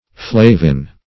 Search Result for " flavin" : Wordnet 3.0 NOUN (1) 1. a ketone that forms the nucleus of certain natural yellow pigments like riboflavin ; The Collaborative International Dictionary of English v.0.48: Flavin \Fla"vin\, n. [L. flavus yellow.]